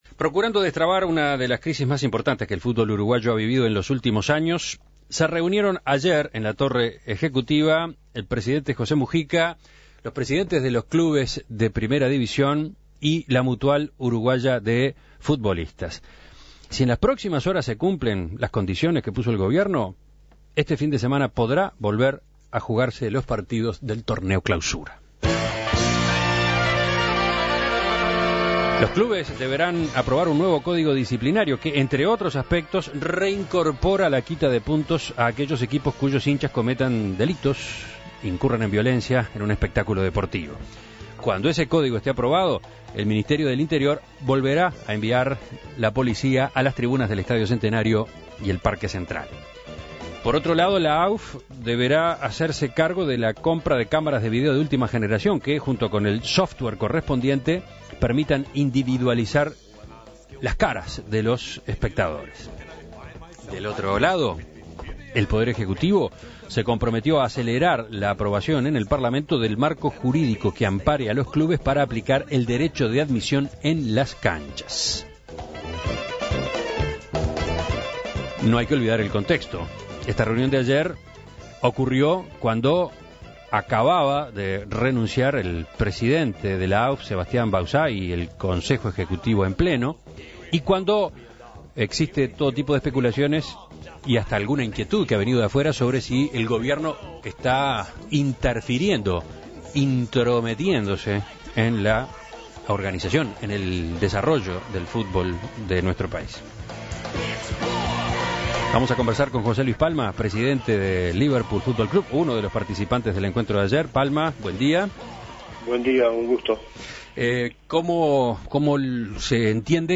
La aprobación de un nuevo Código Disciplinario, la compra de cámaras de video para individualizar a los violentos y el consecuente software para su ejecución, parecen ser las condiciones inminentes. En diálogo con En Perspectiva